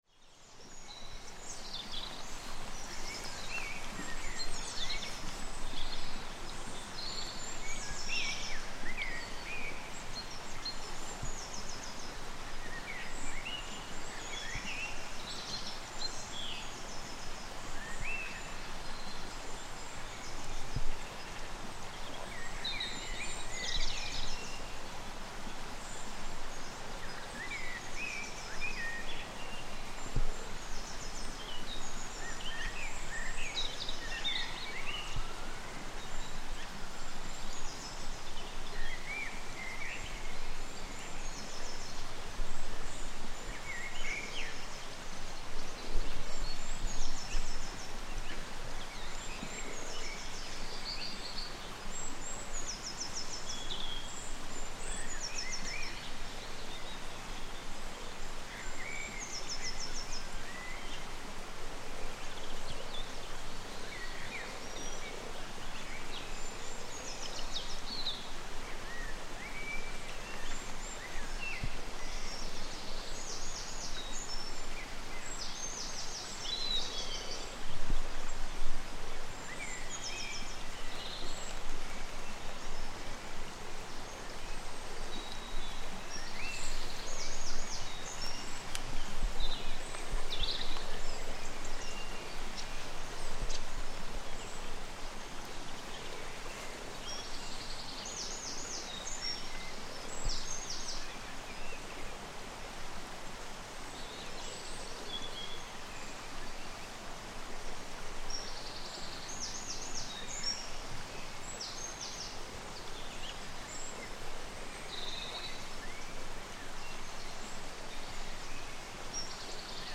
The intense humming of frogs